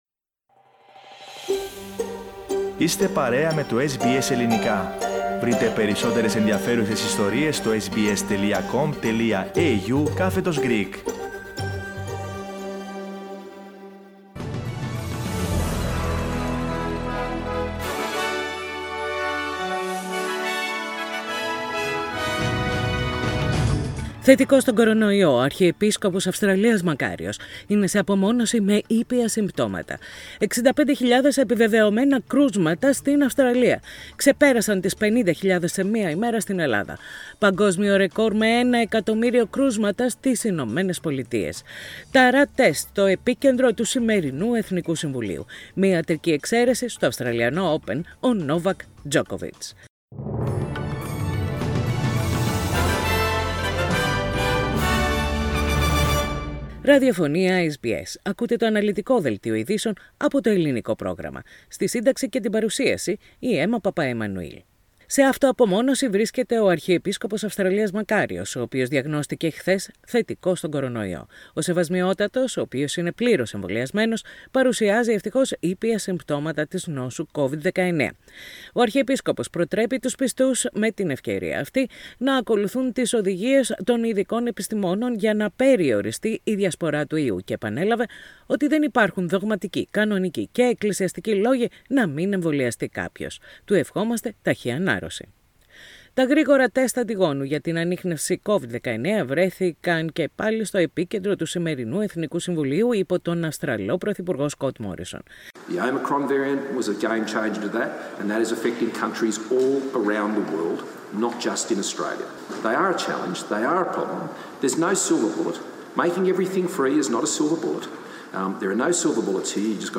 The detailed bulletin of the day, with the main news from Australia, Greece, Cyprus and the international arena.